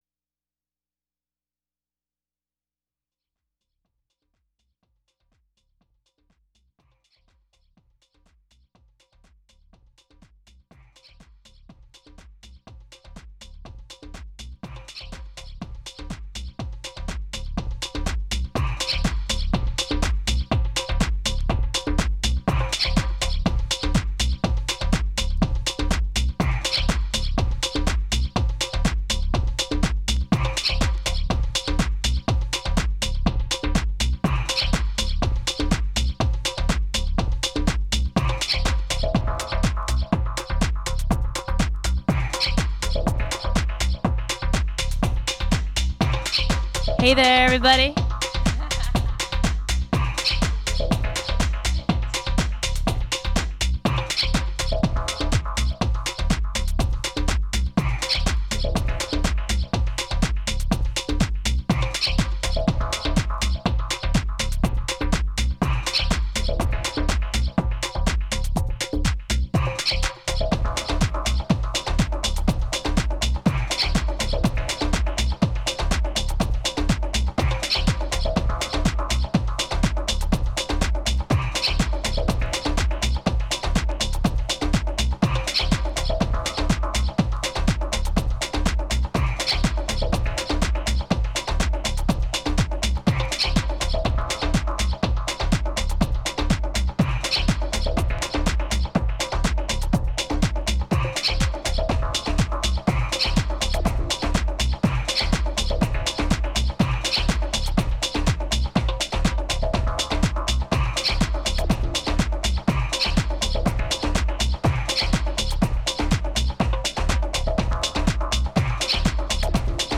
Disco/House Electronic